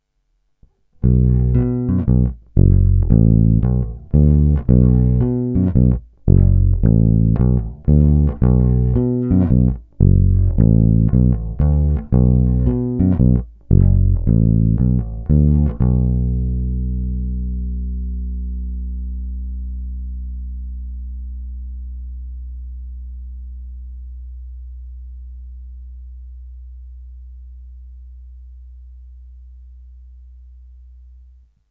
Následující nahrávky, není-li řečeno jinak, jsou provedeny rovnou do zvukové karty, jen normalizovány, jinak ponechány v původním stavu bez postprocesingu. Tónová clona byla vždy plně otevřená.
Krkový snímač